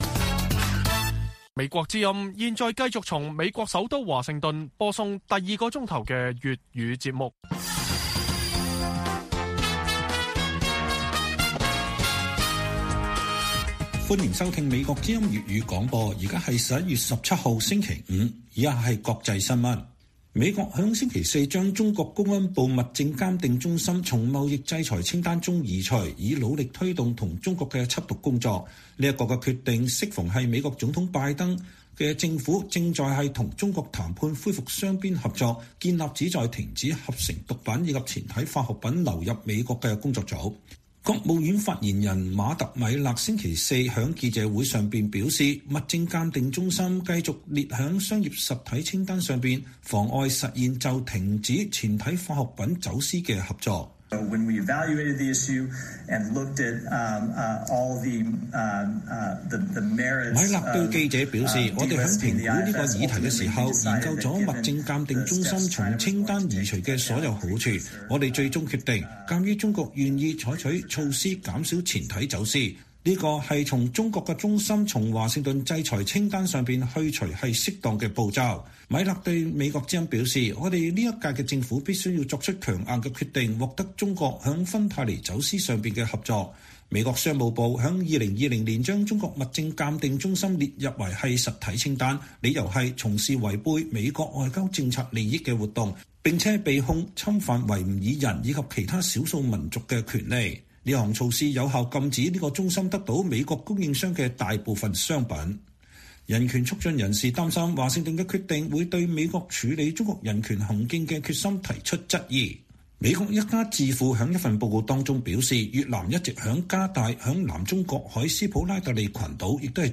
粵語新聞 晚上10-11點：拜習會後美中聲明差異